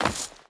Stone Chain Land.wav